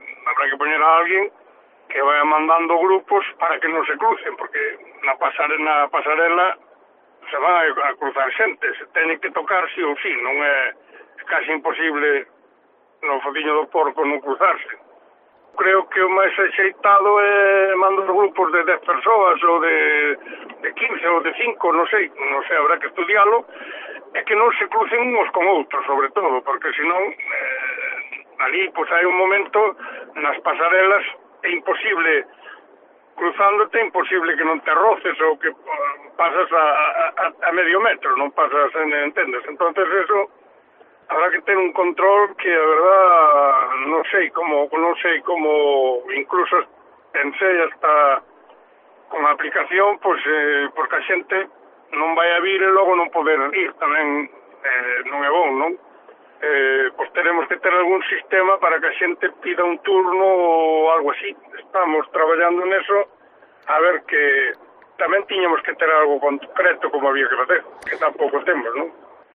Declaraciones de Jesús Novo, alcalde de O Vicedo